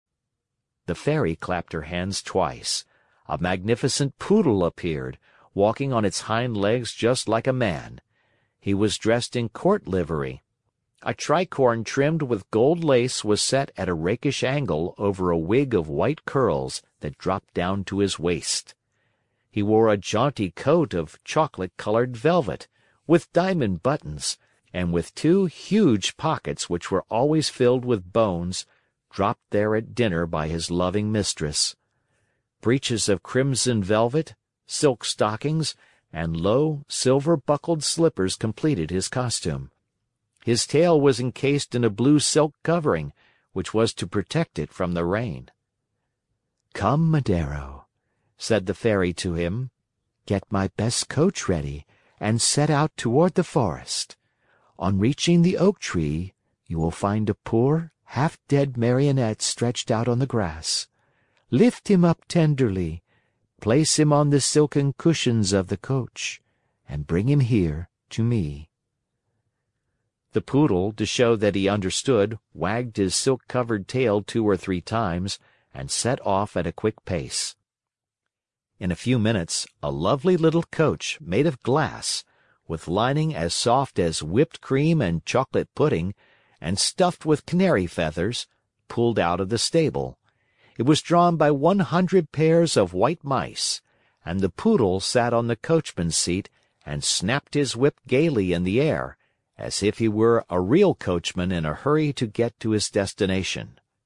在线英语听力室木偶奇遇记 第41期:蓝发少女施救(2)的听力文件下载,《木偶奇遇记》是双语童话故事的有声读物，包含中英字幕以及英语听力MP3,是听故事学英语的极好素材。